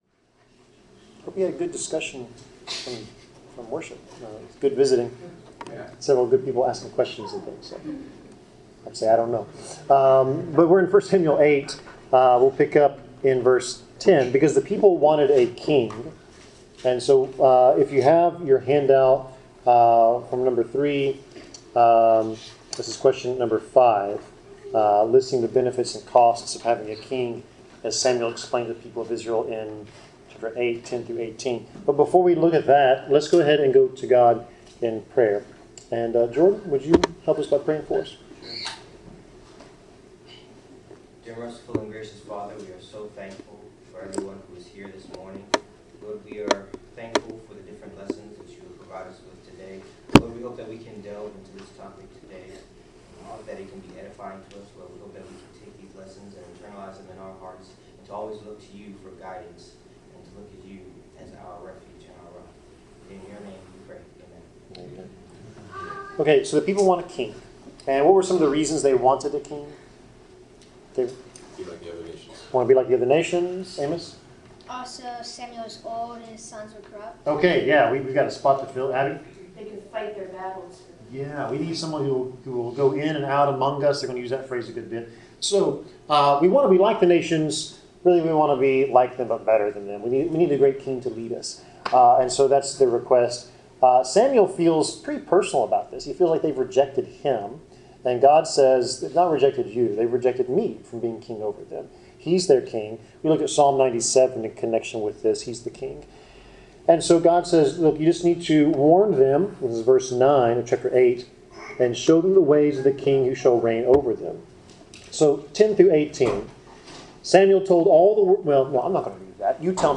Bible class: 1 Samuel 9-10
Service Type: Bible Class